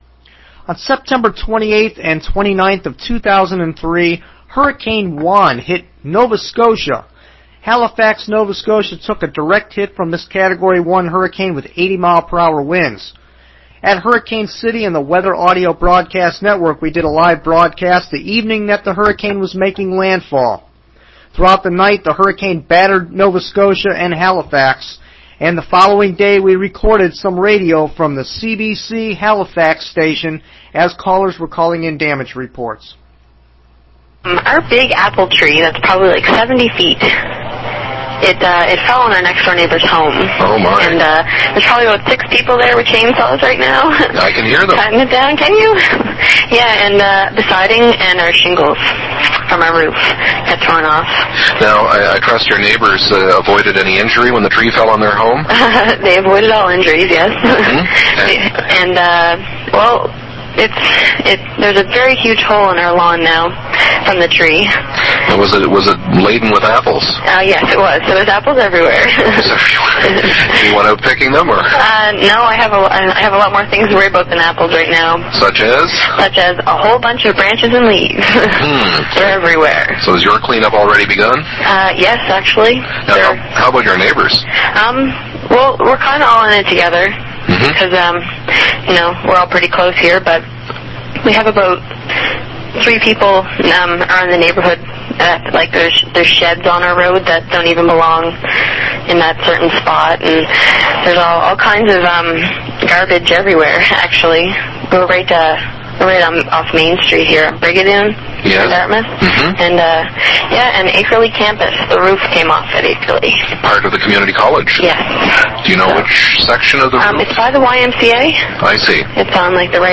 CBC radio aftermath | Damage shots | CBC special
hurricane_juan_cbc_halifax_radio_2003.mp3